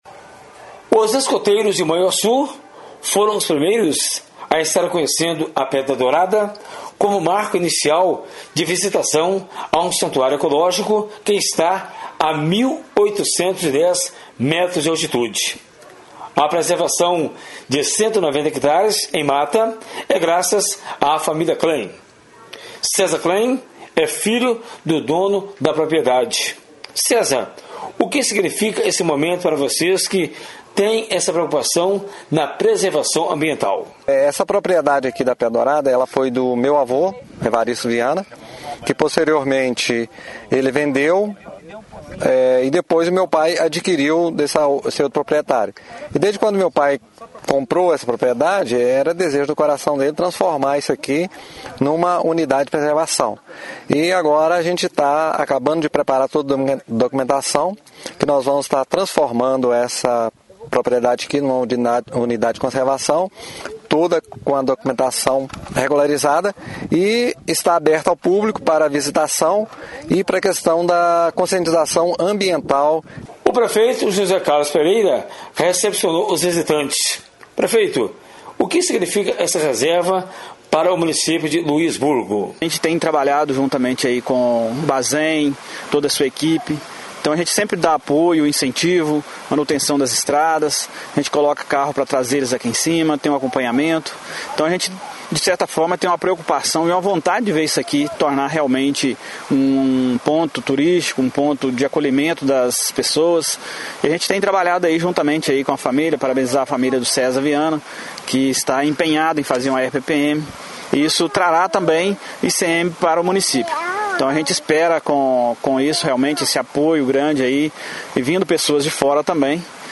Abaixo, confira a matéria em áudio